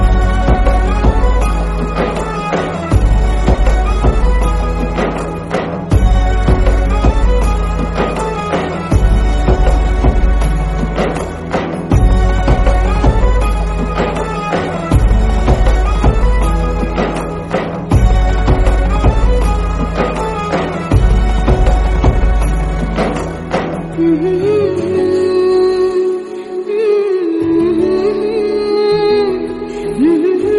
Set this soulful and trending tune as your mobile ringtone.